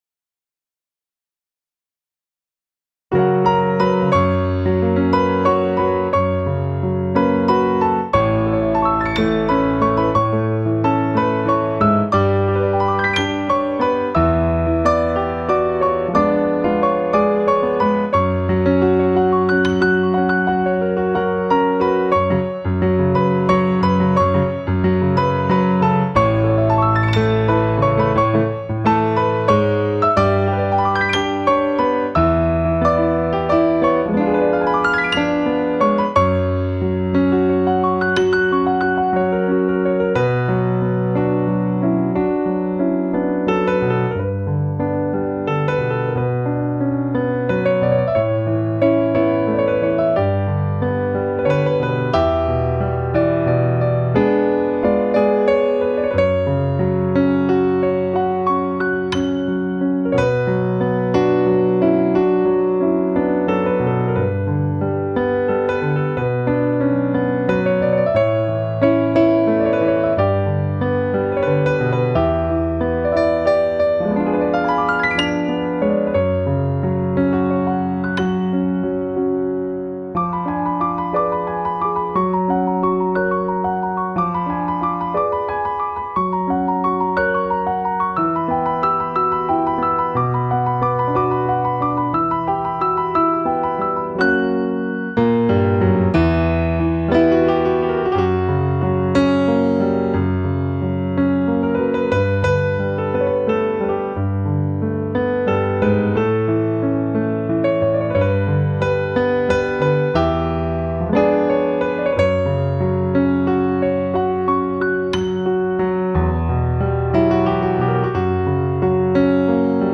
ورژن پیانو